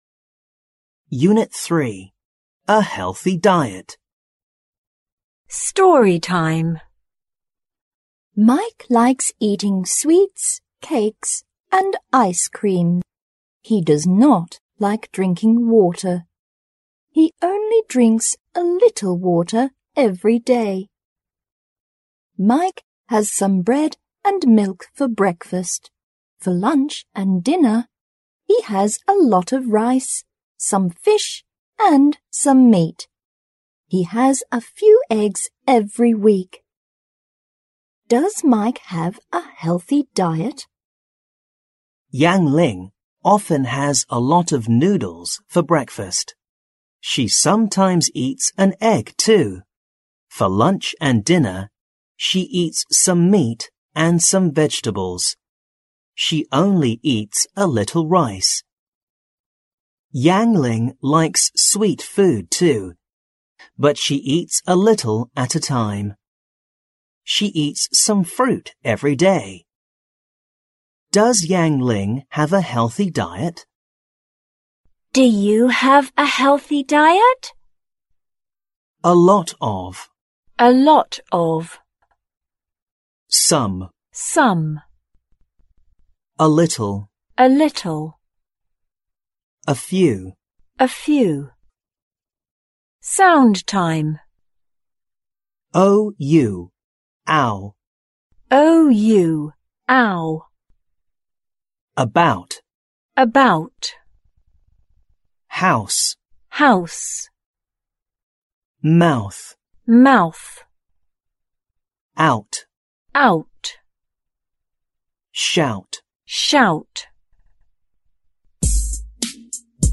六年级英语下Unit3课文.mp3